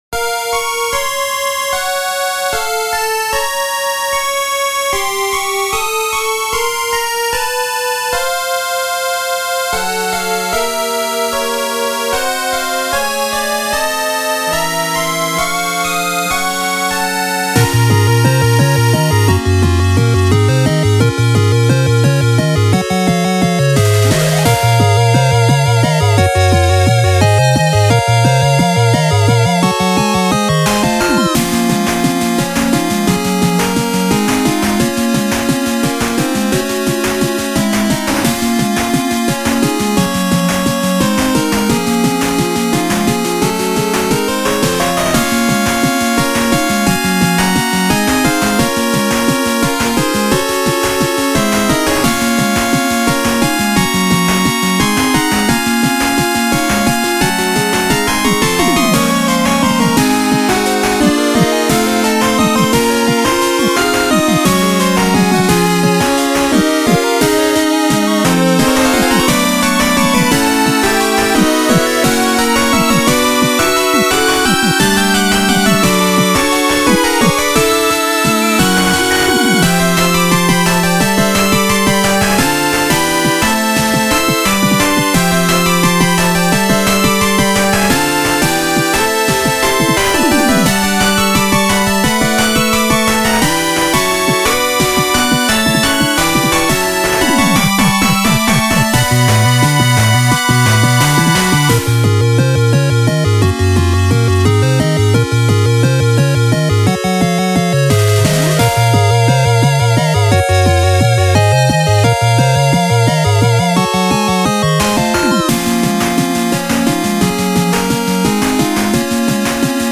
ジャンルファミコン風、8-Bit
BPM１５０→１７４
使用楽器8-Bit音源
ファミコン風(8-Bit music)